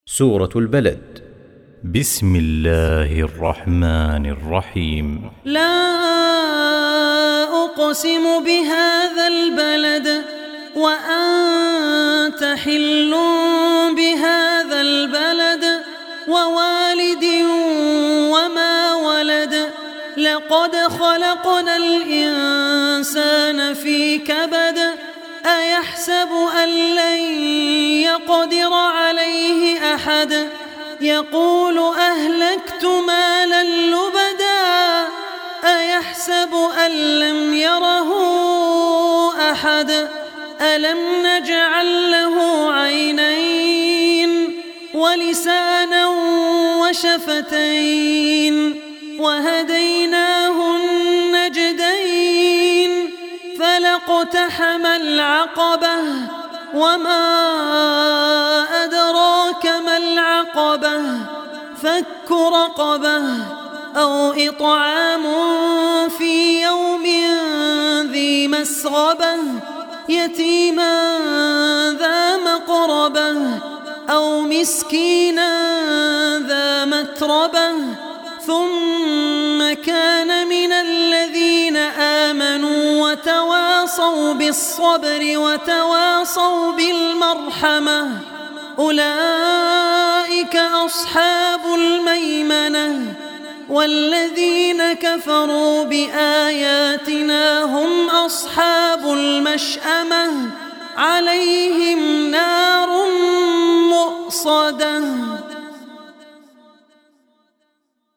Surah Balad Recitation by Abdur Rehman Al Ossi
Surah Balad, listen online mp3 tilawat / recitation in the voice of Abdur Rehman Al Ossi.
90-surah-balad.mp3